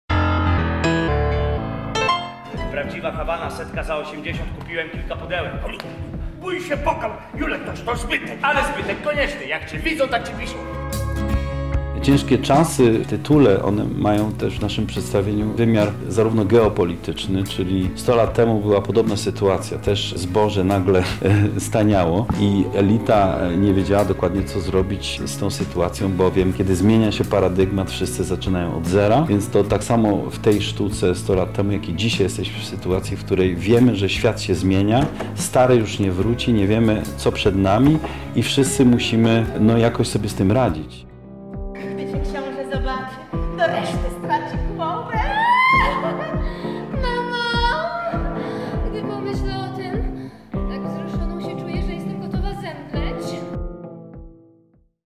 Rozmawialiśmy z reżyserem.